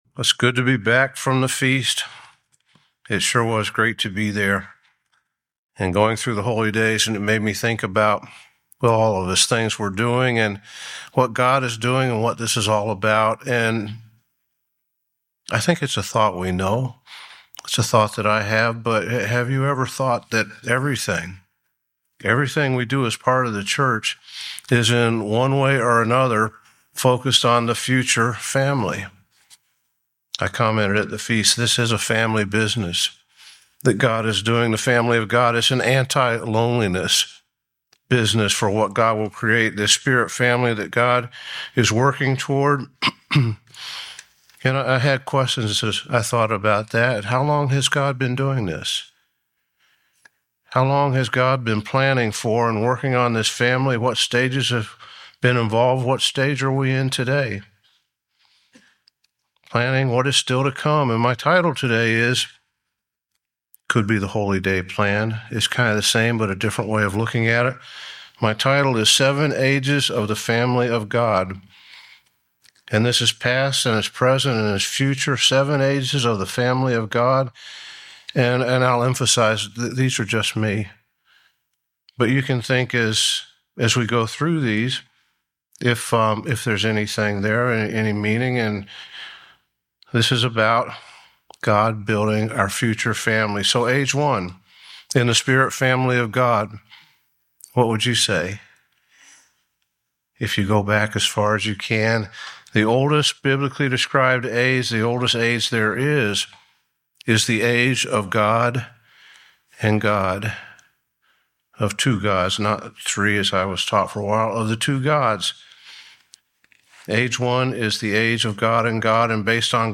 Sermons
Given in Atlanta, GA Buford, GA